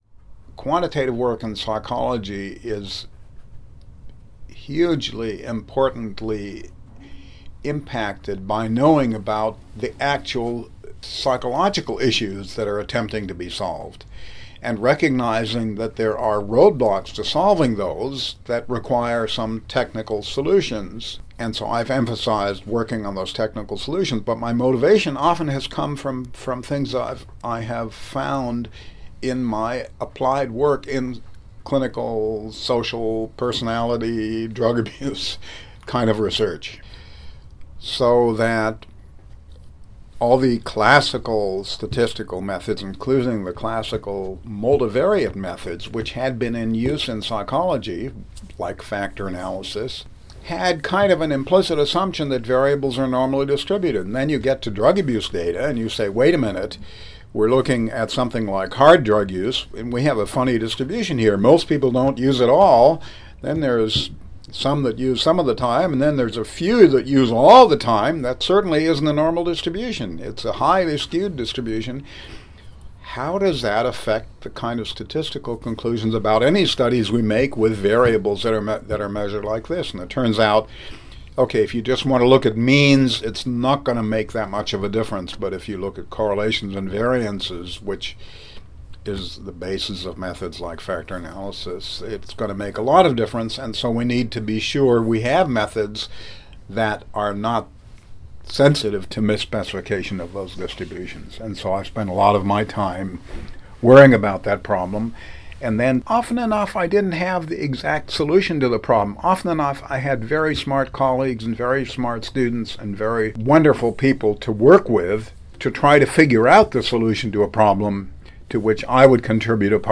He describes that mathematical journey to developing SEM and the related stat package EQS in this next excerpt: